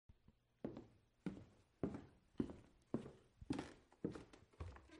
Footsteps on Wood
Footsteps on Wood is a free foley sound effect available for download in MP3 format.
020_footsteps_on_wood.mp3